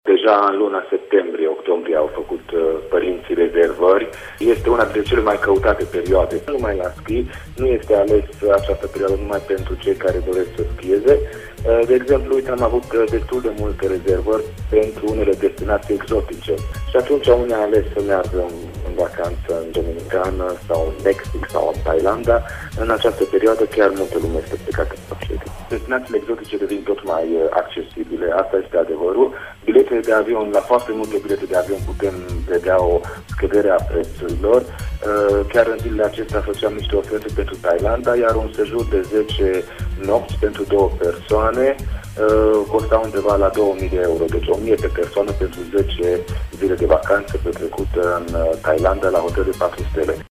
Reprezentantul unei agenții de turism